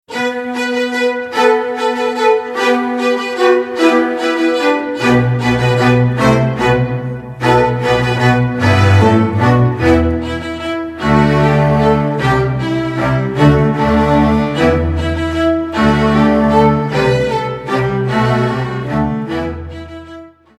Besetzung: Streichorchester